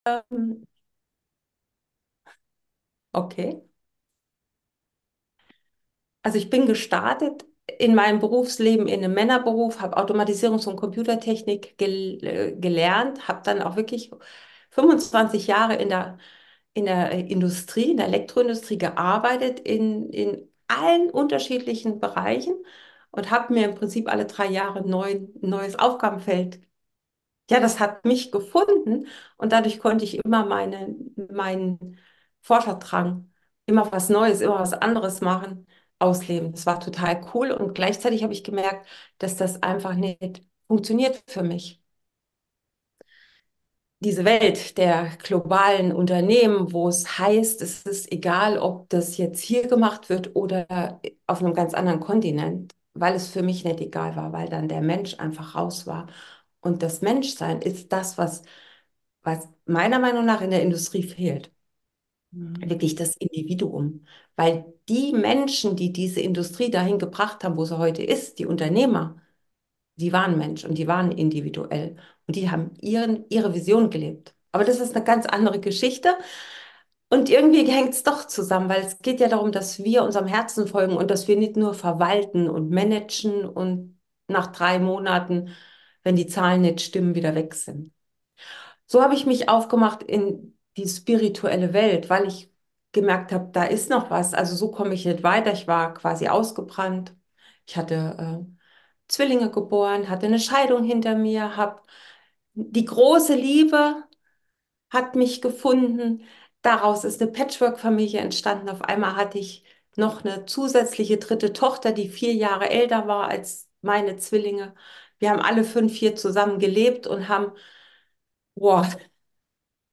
Sie sagte JA zu einem Podcast-Interview!